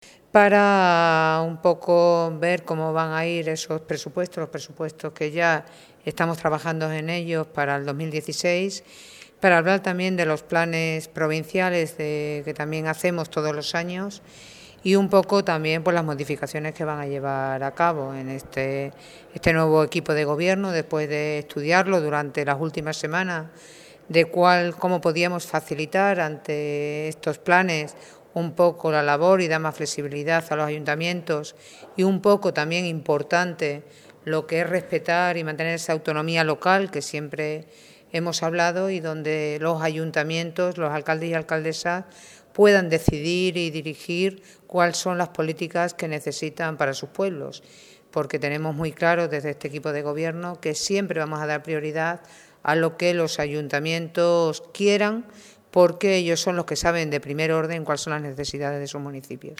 CORTES DE VOZ
El primer encuentro ha tenido lugar en Trujillo con los ediles de la zona y donde la Presidenta ha explicado las principales líneas de actuación del Gobierno Provincial y ha abierto el turno de palabra para escuchar las prioridades de los municipios.